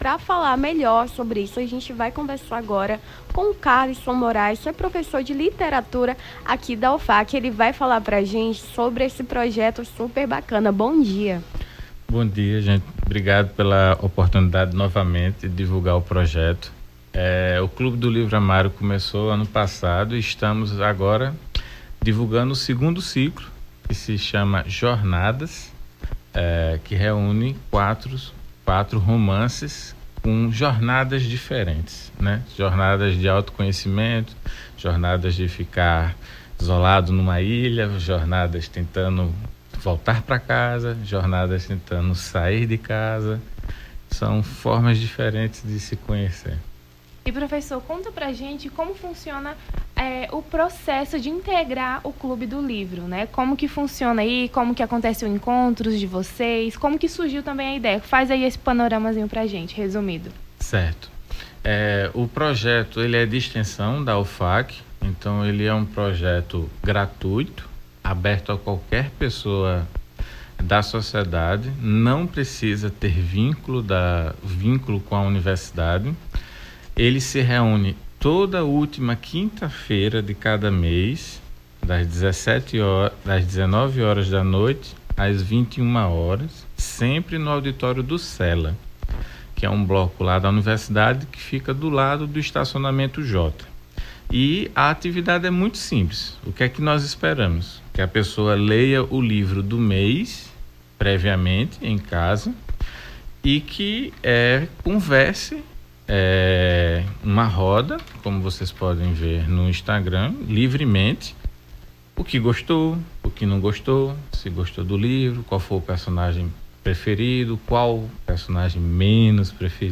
Nome do Artista - CENSURA - ENTREVISTA (CICLO II JORNADAS) 31-01-24.mp3